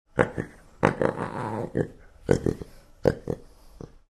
Звуки пантеры
Ворчливая пантера звук черного леопарда